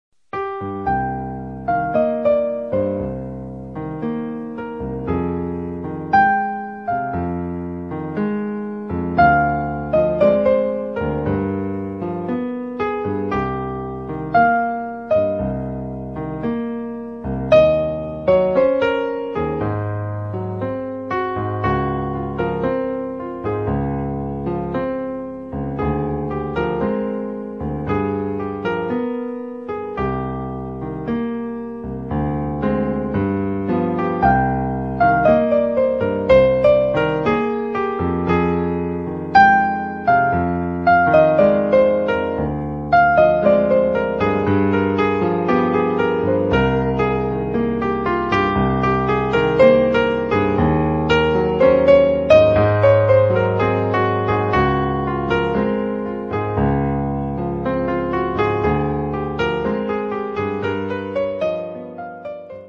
musikalische Untermalung am Klavier